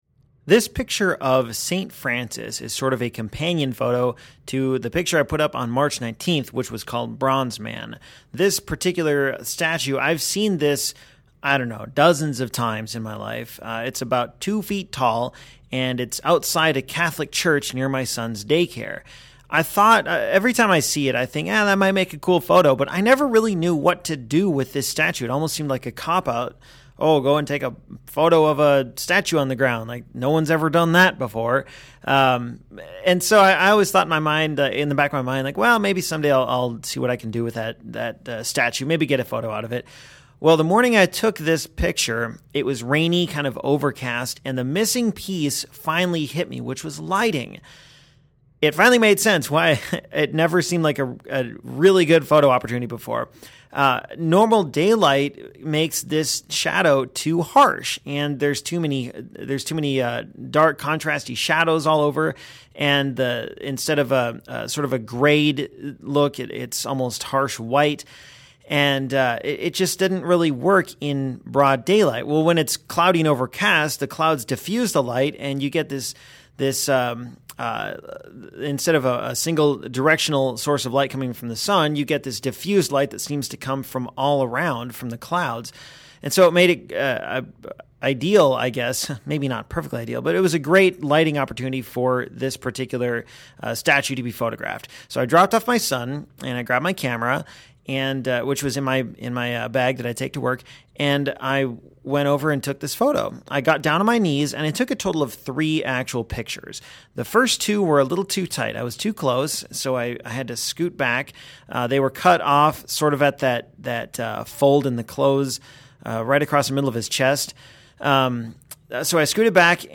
Side note: I apologize for the chime noise that pops up near the end of the audio commentary. That was my phone with a new text message, which I forgot to silence.